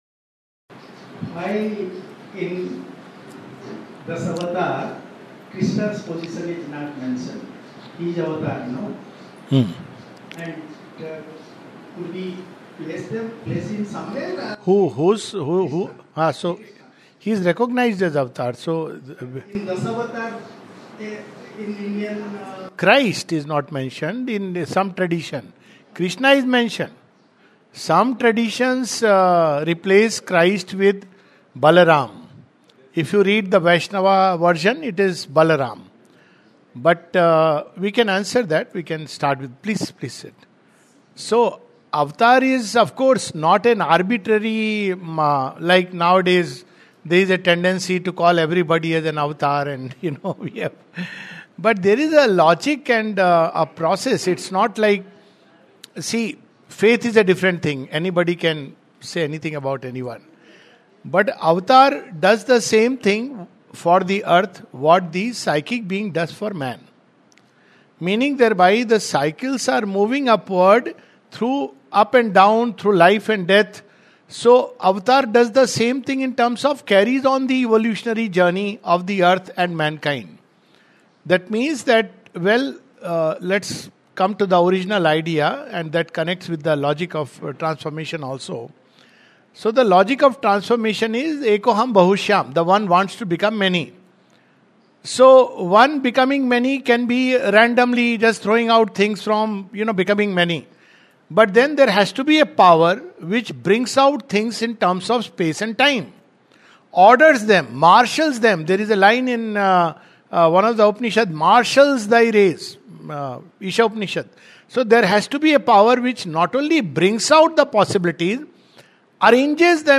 A series of four talks on Sri Aurobindo's vision of the future of earth and humanity, with the help of epic poem Savitri which contains Sri Aurobindo's ultimate revelations.
at the Sri Aurobindo Dham, Karnataka on August 30-31, 2025.